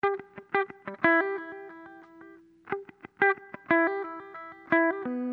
Sons et loops gratuits de guitares rythmiques 100bpm
Guitare rythmique 67